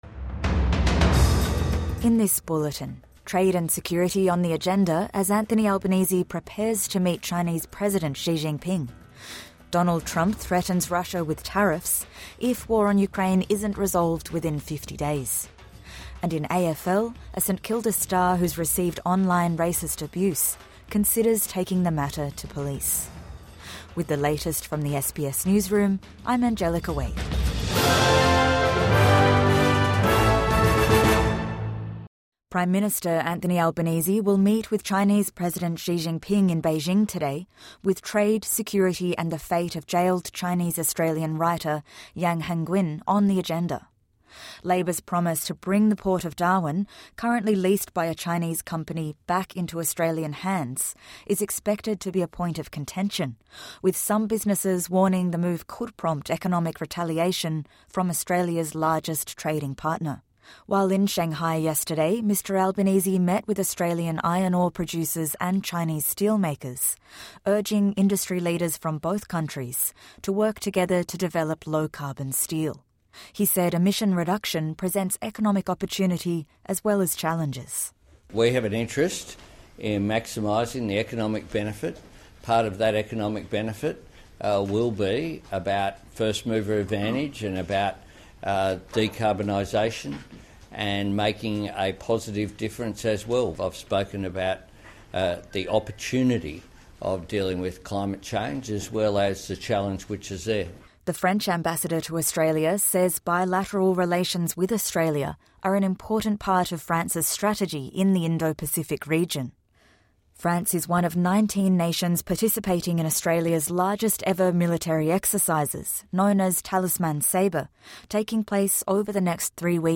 Anthony Albanese to meet China's President Xi Jinping | Morning News Bulletin 15 July 2025